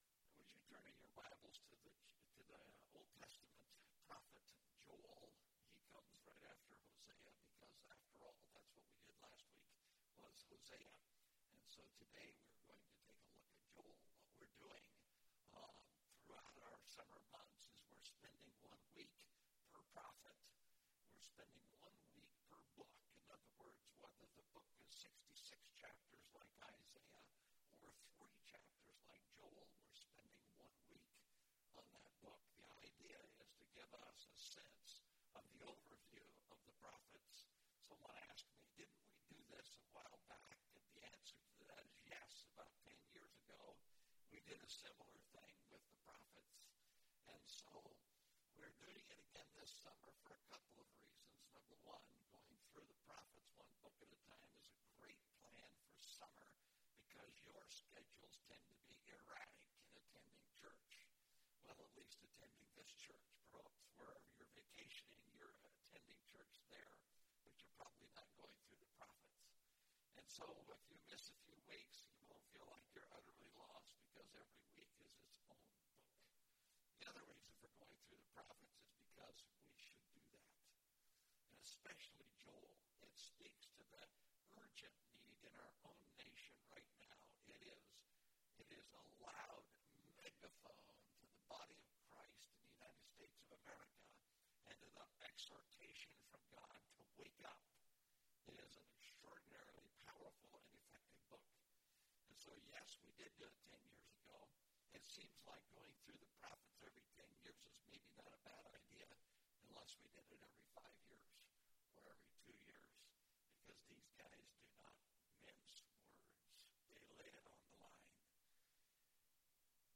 The Prophets – Joel (835 BC) (Joel) – Mountain View Baptist Church